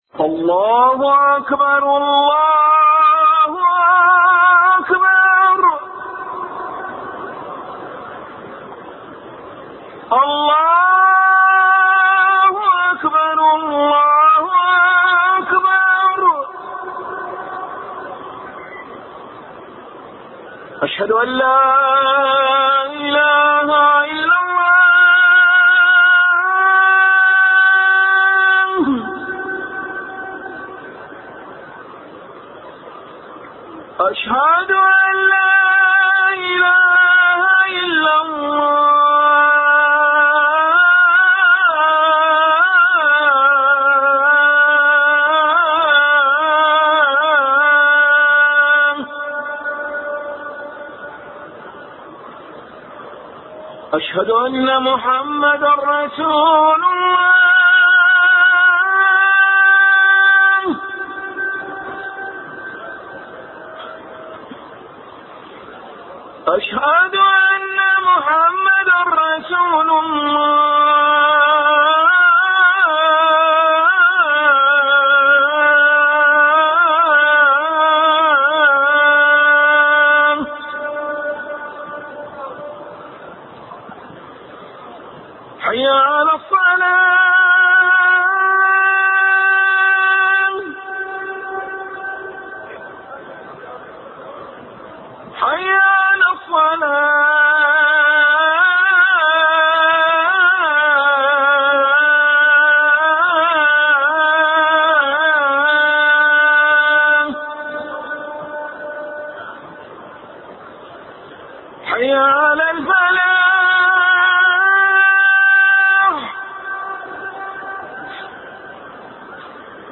أناشيد ونغمات
عنوان المادة أذان الحرم المكي5